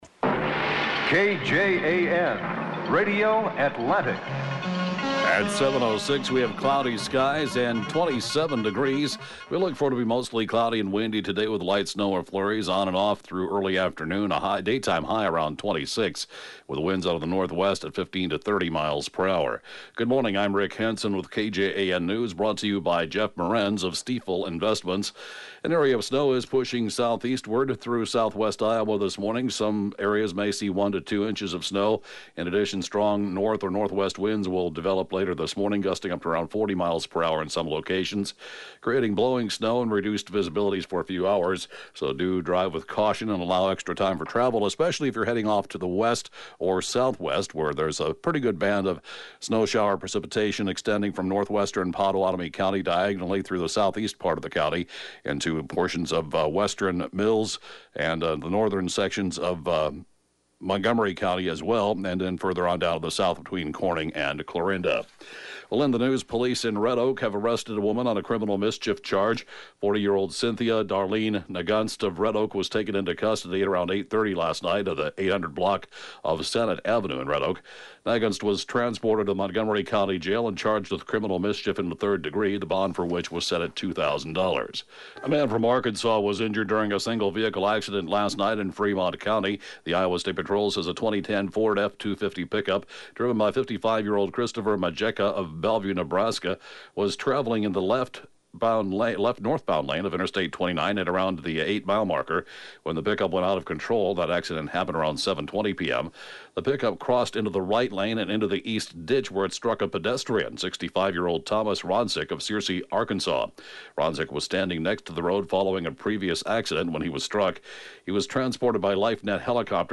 The area’s latest and/or top news stories at 7:06-a.m.